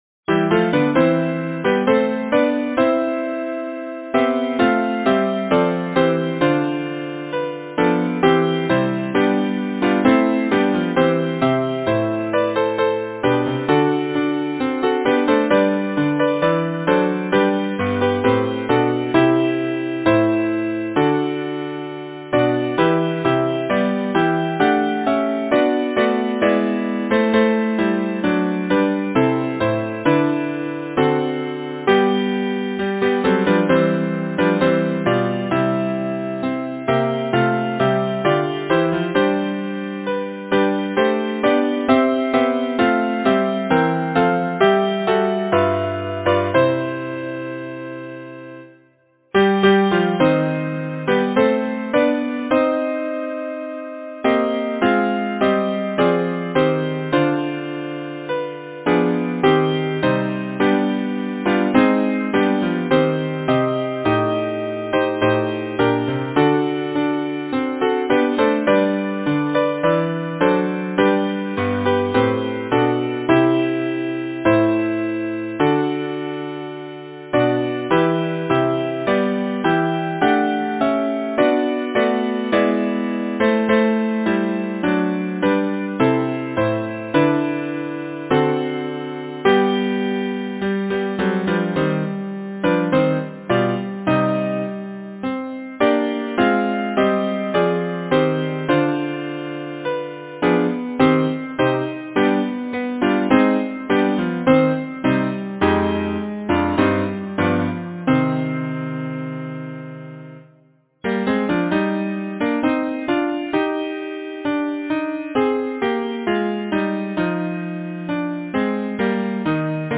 Title: The Two Stars Composer: Walter Cecil Macfarren Lyricist: Frederic Edward Weatherly Number of voices: 4vv Voicing: SATB Genre: Secular, Partsong
Language: English Instruments: A cappella